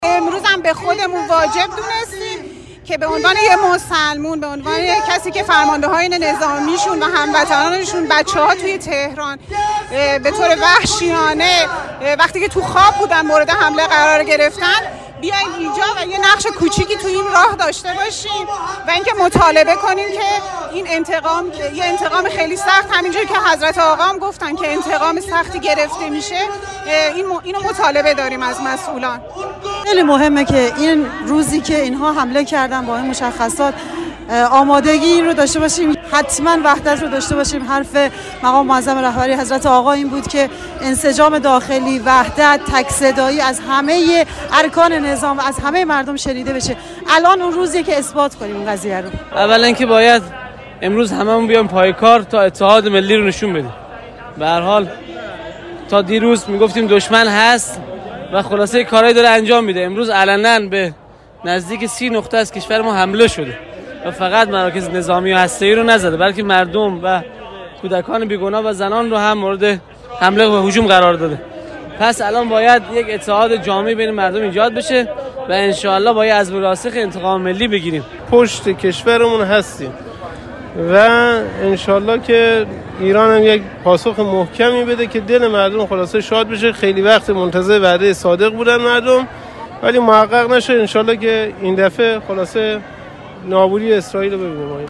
نظر چندتن از شهروندان حاضر در این راهپیمایی جویا شده اند که باهم می شنویم